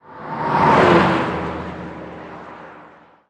car5.ogg